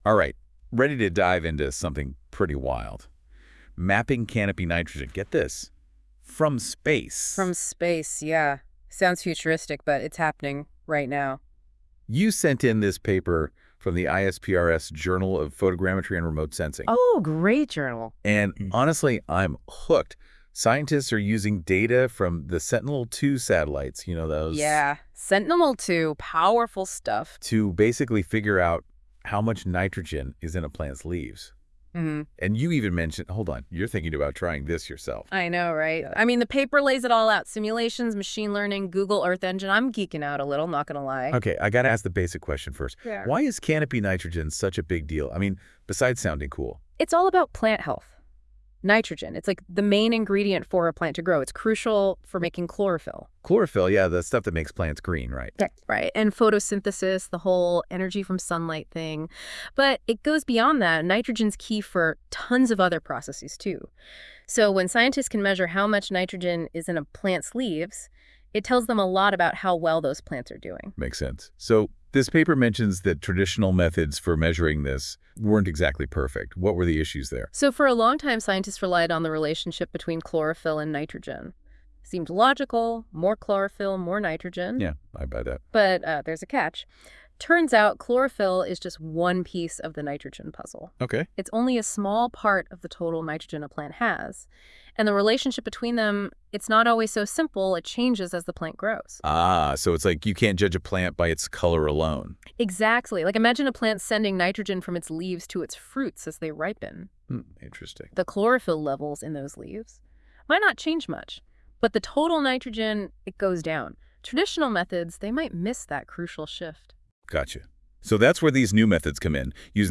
音频内容由AI自动生成，仅供参考。